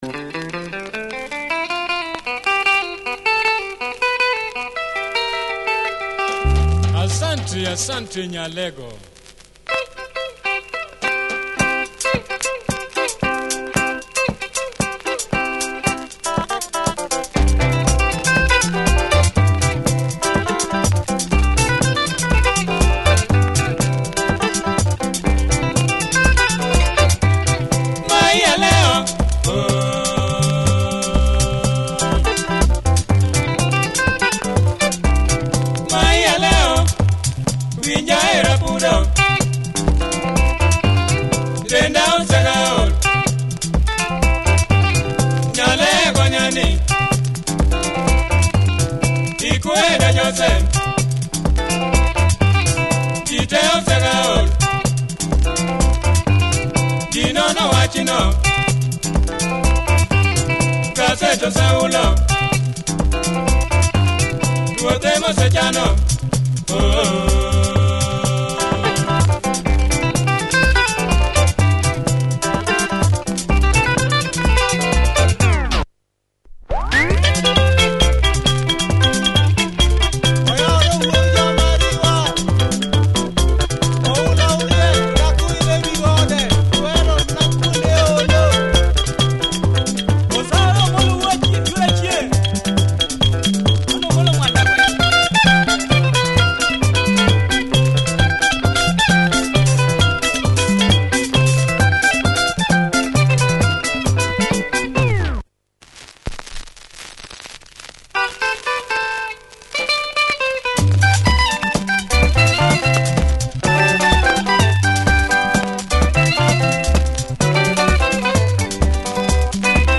Quality luo benga with great breakdowns.
Some noise in the start of side-a